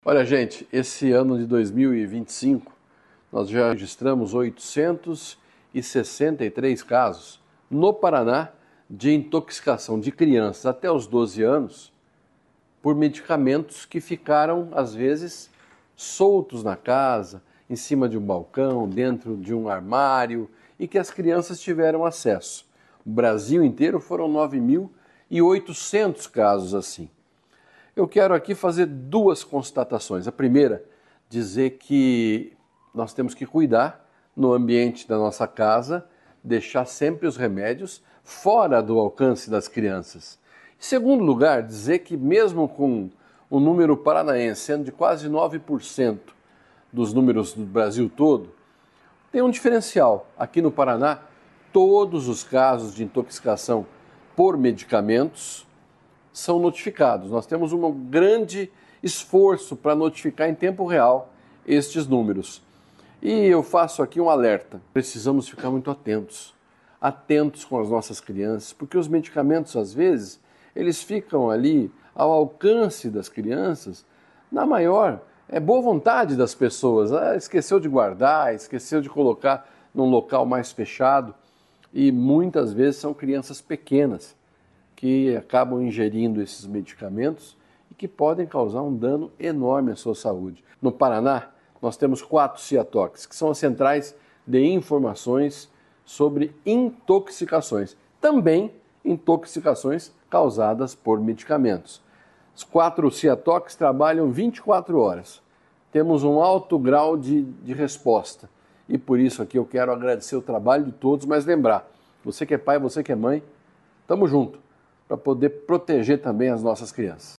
Sonora do secretário da Saúde, Beto Preto, alertando para o perigo de intoxicação infantil por medicamentos